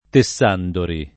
[ te SS# ndori ]